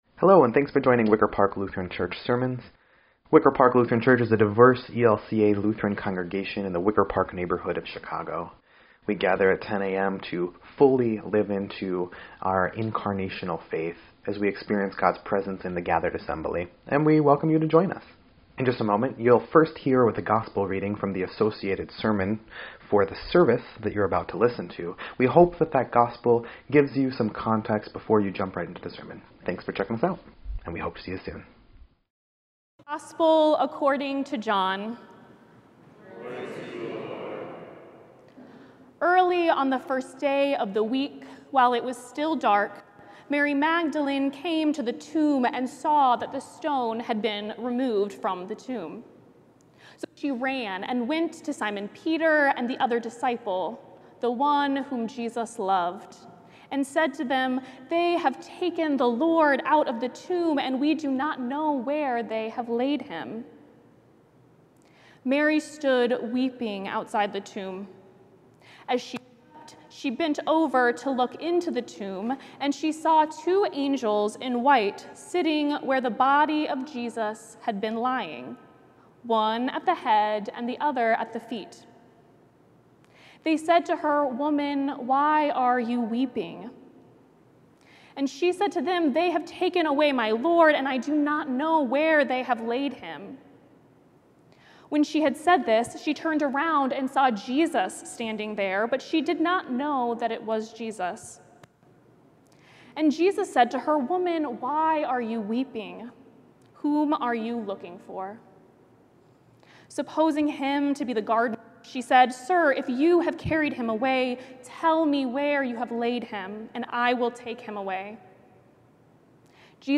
7.20.25-Sermon_EDIT.mp3